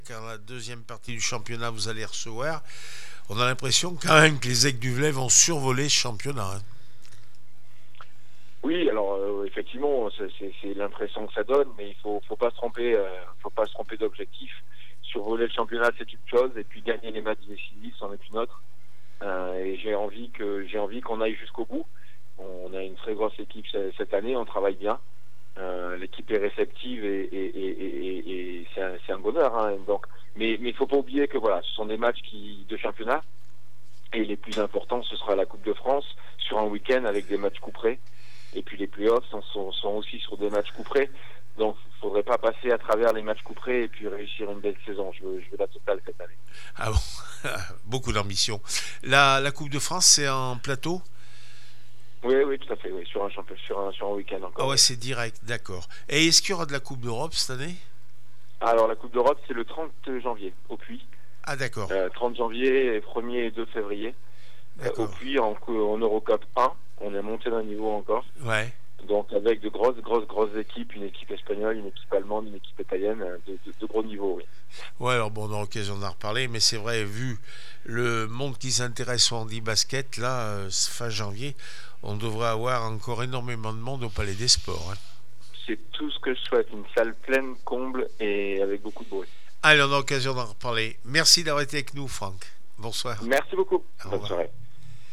14 décembre 2024   1 - Sport, 1 - Vos interviews